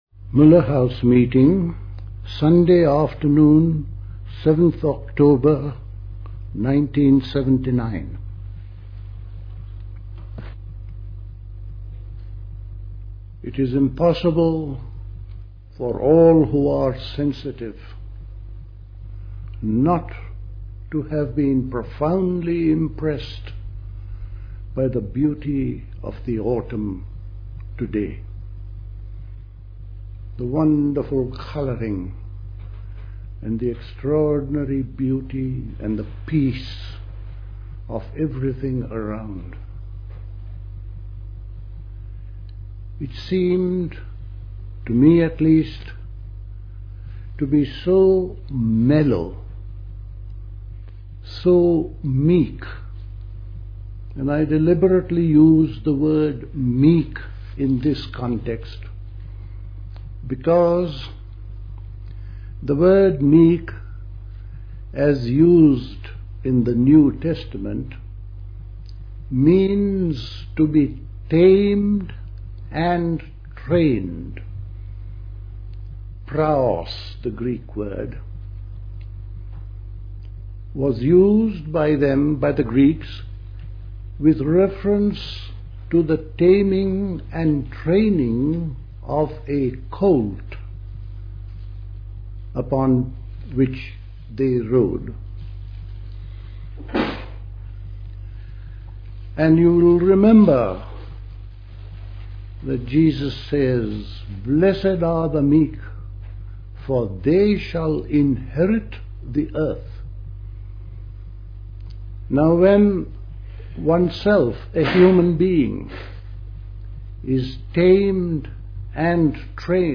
at Elmau, Bavaria